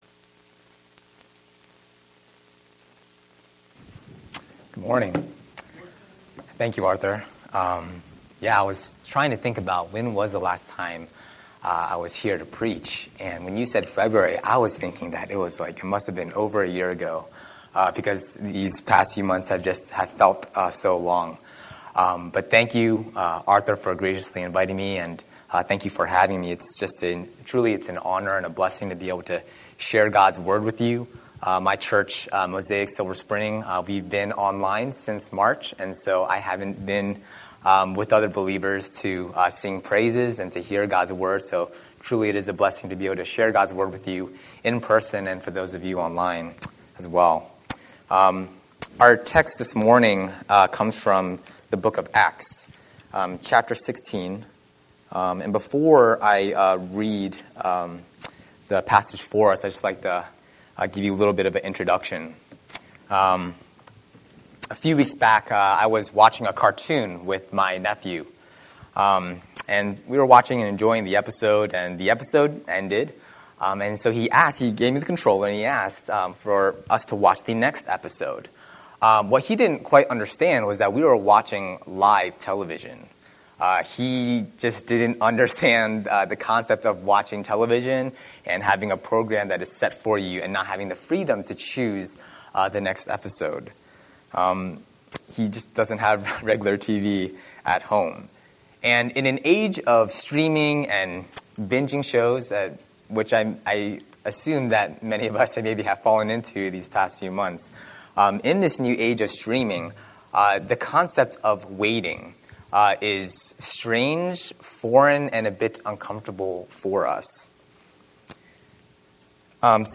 Scripture: Acts 16:13–40 Series: Sunday Sermon